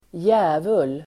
Uttal: [²j'ä:vul]